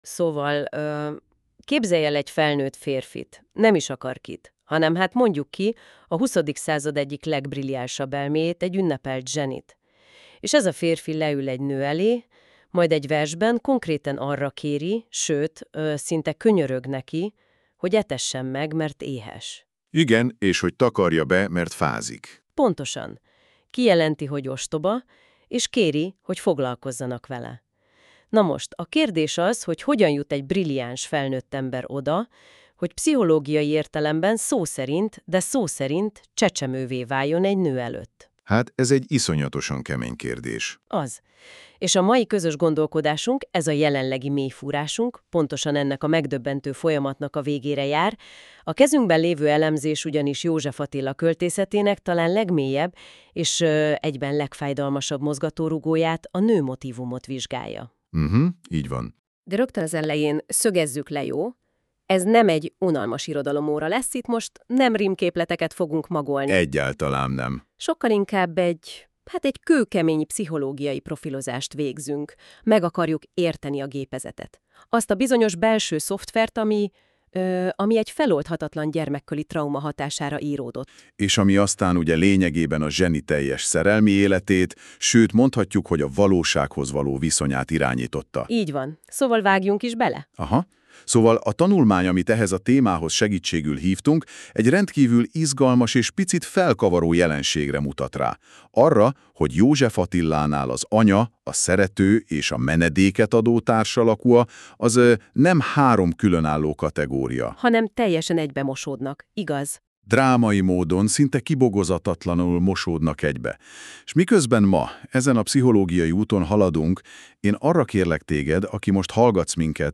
Két házigazda beszélgetése magyarul.